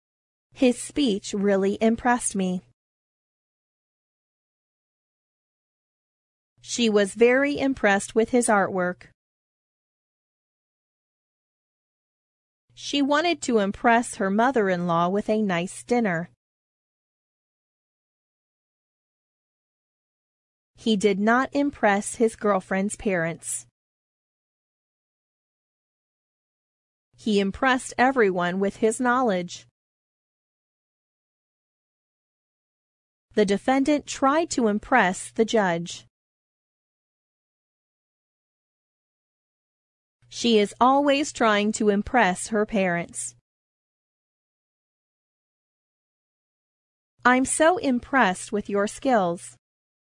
impress-pause.mp3